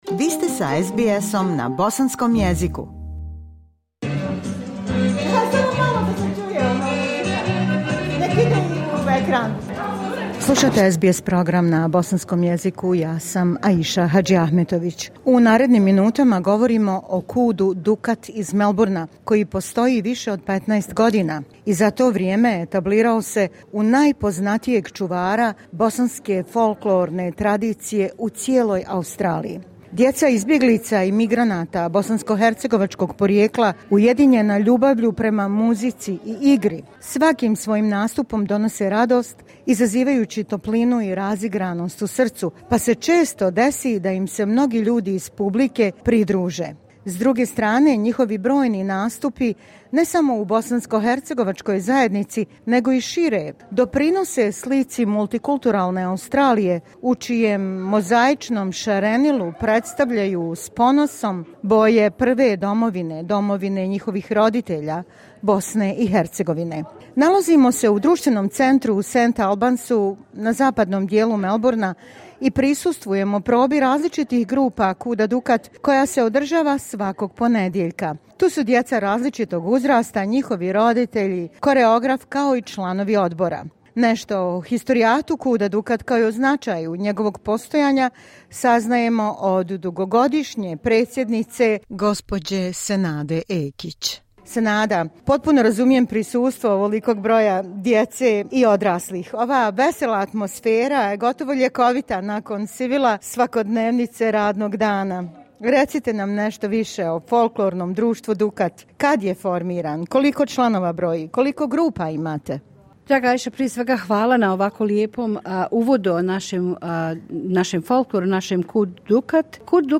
Reportaža snimljena na njihovoj probi u društvenom centru u St Albansu, na zapadu Melbournea, gdje se pripremaju za godišnju zabavu KUD-a i obilježavanje Dana državnosti BiH, najviše svjedoči o dubokim prijateljskim vezama koje ih vežu i pozitivnim vibracijama.
Proba za nastup KUD-a Dukat povodom Dana državnosti BiH, St Albans Community Centre, novembar 2024.